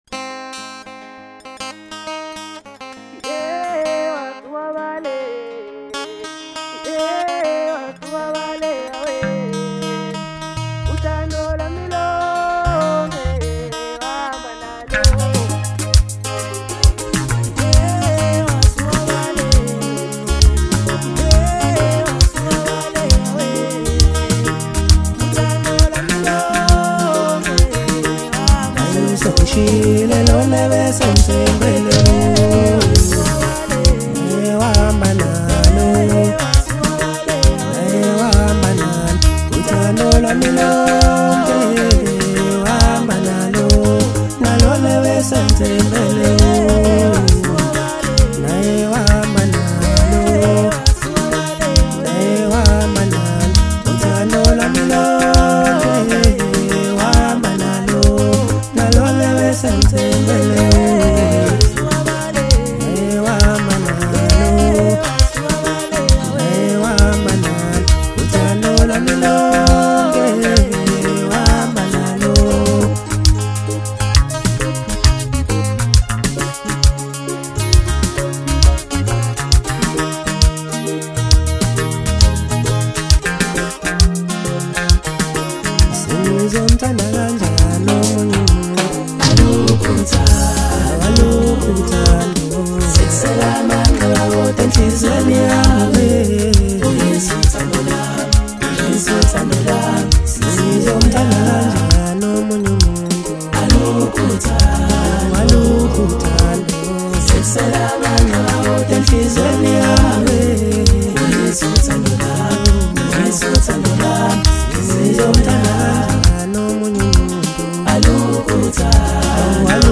Home » DJ Mix » Hip Hop » Maskandi